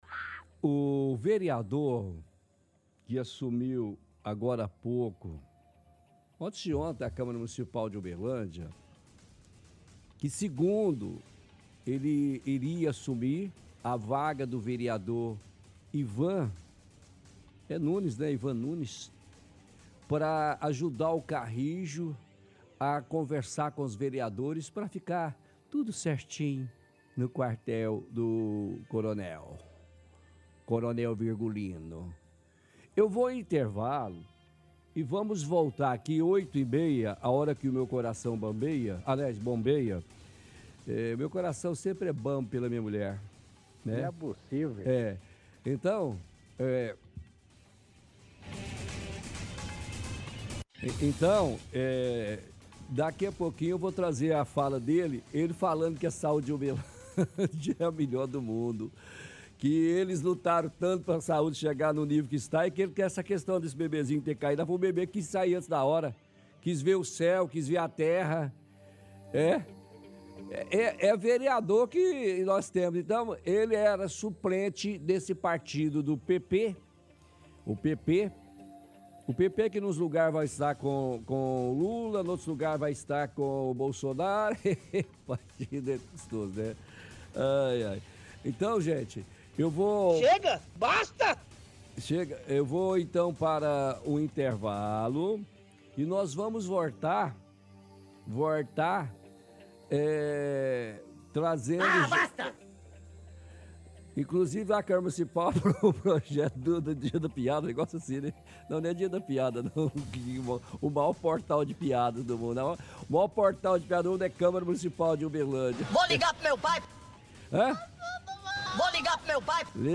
– Transmite áudio da fala do vereador.
– Áudios de ouvintes criticando o vereador Abatênio.